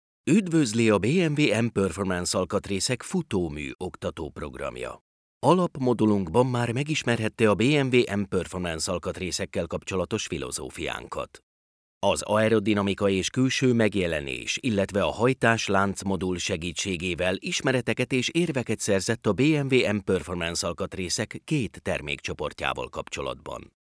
hungarian voice actor, hungarian voice over talent
Sprechprobe: eLearning (Muttersprache):
I born and live here so I have no accent at all.